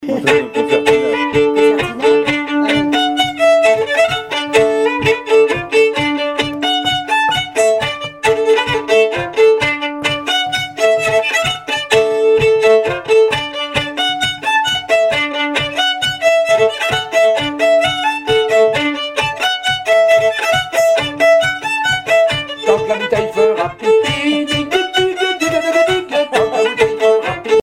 danse : branle : avant-deux
Pièces instrumentales à plusieurs violons
Pièce musicale inédite